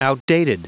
Prononciation du mot outdated en anglais (fichier audio)
Prononciation du mot : outdated